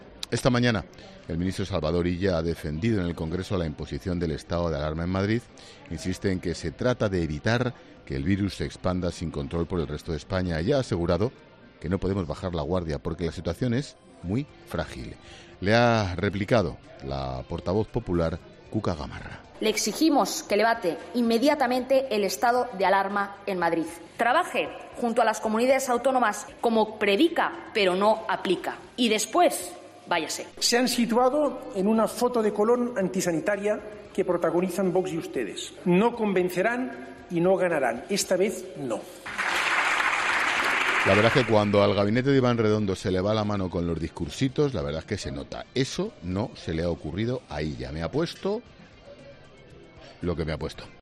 En su monólogo inicial de este jueves en 'La Linterna', Ángel Expósito ha querido comentar la comparecencia de Salvador Illa en el Congreso de los Diputados.
A continuación, se ha escuchado al ministro afirmar lo siguiente: “Se han situado en una foto de Colón antisanitaria que protagonizan Vox y ustedes. No convencerán y no ganarán. Esta vez no”.